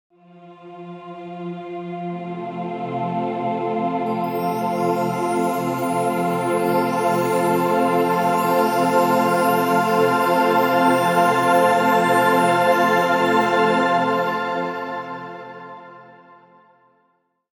Cinematic Swell Reveal Transition Sound Effect
Description: Cinematic swell reveal transition sound effect. Revealed cinematic swell transition features a textured whoosh with a mysterious metallic resonance. It works perfectly for film trailers, suspenseful scene shifts, TikTok, Shorts, Reels, and atmospheric sound design.
Genres: Sound Logo
Cinematic-swell-reveal-transition-sound-effect.mp3